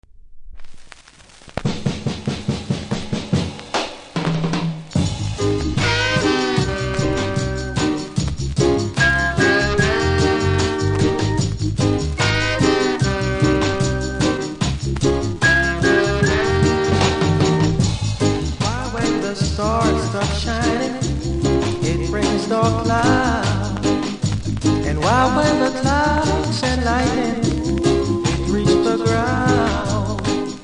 序盤キズによるノイズありますので試聴で確認下さい。